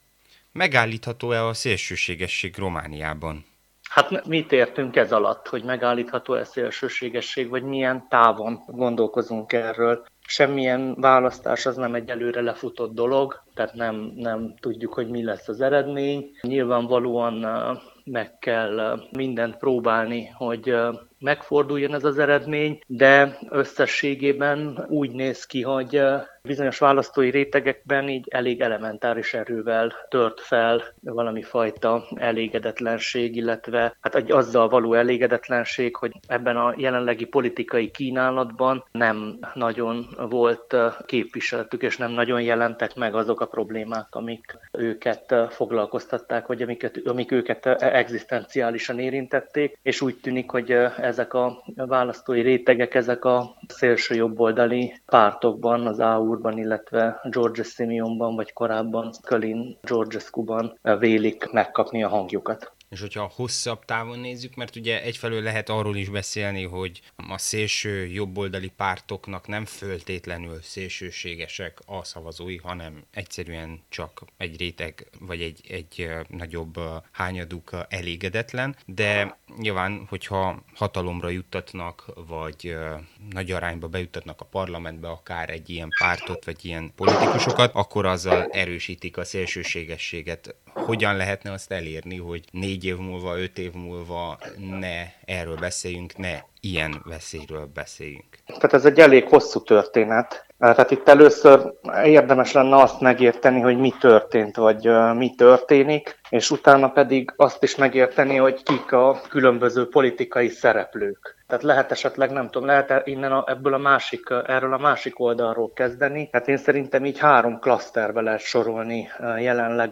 interjúja